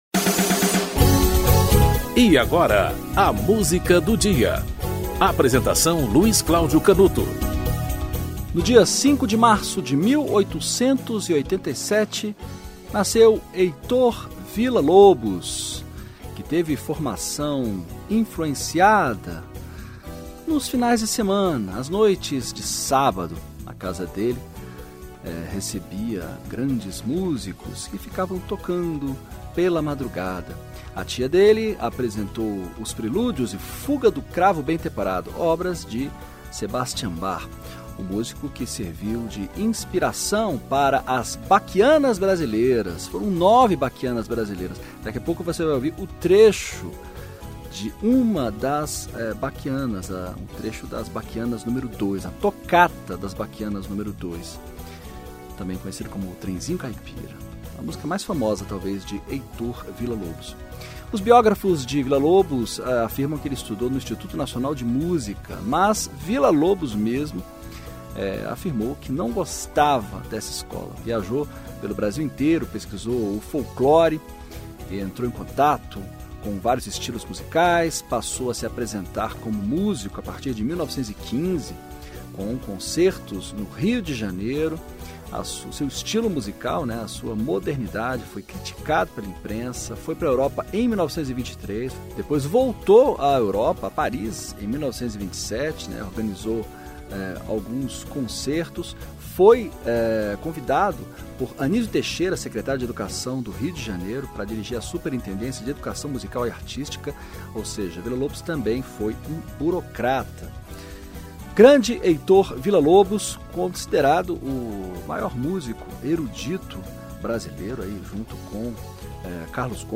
Enrique Arturo Diemecke - Bachianas Brasileiras n. 2 (Heitor Villa Lobos)